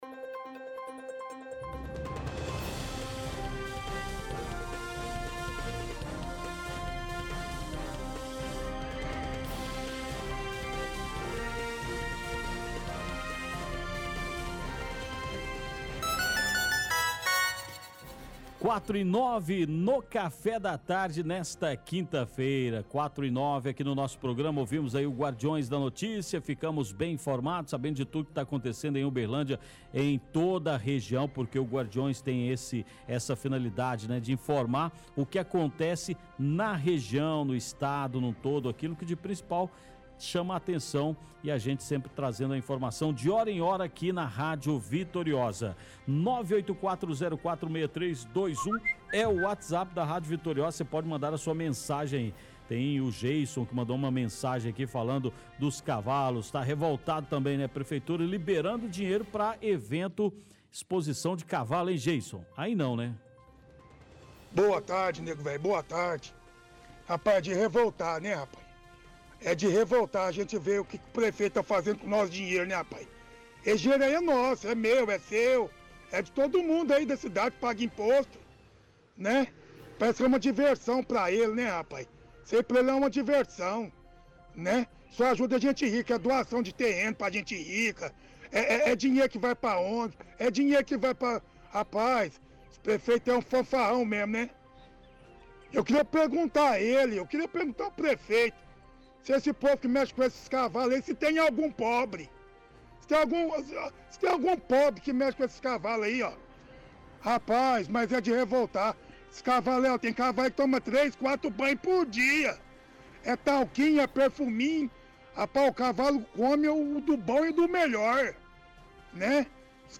Participação do Ouvinte – Corrida de Cavalos
-Apresentador fala que ele precisa procurar um advogado para saber o que ele pode fazer.